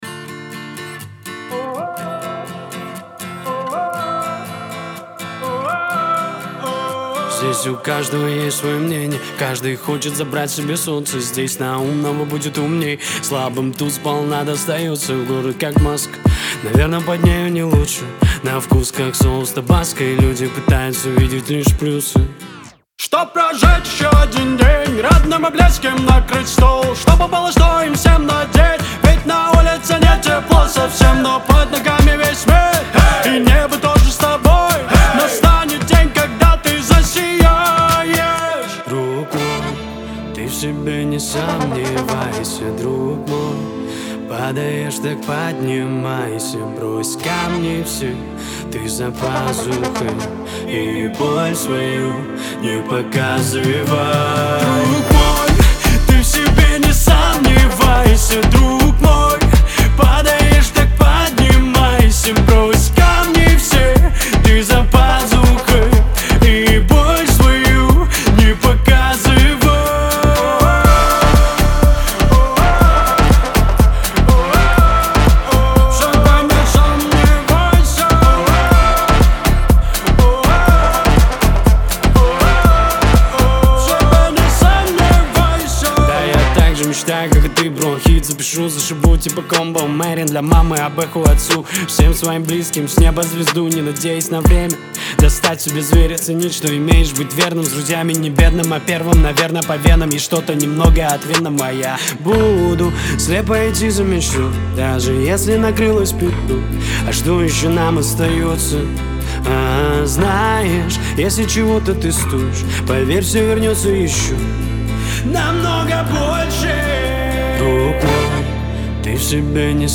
динамичная и энергичная песня